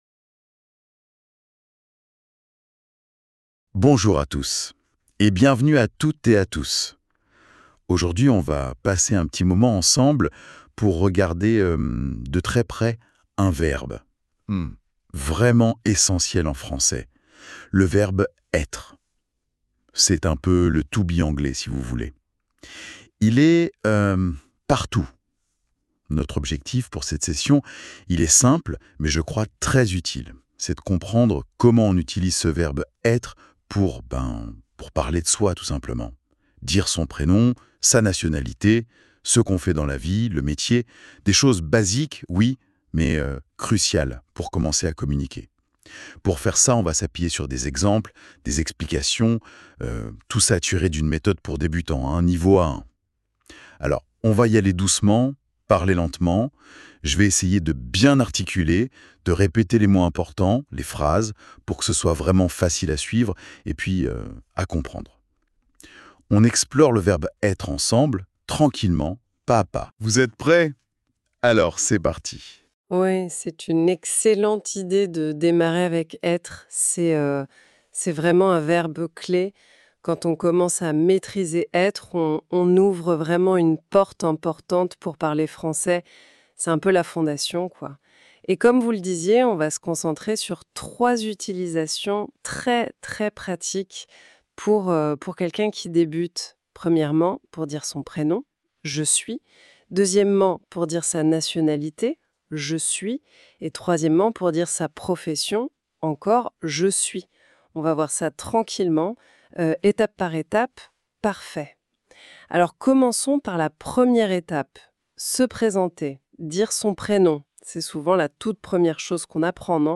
Apprenez le français avec un dialogue pratique + PDF.
Le-verbe-eTRE-en-francais-to-be-lecon-A1-pour-debutants-.mp3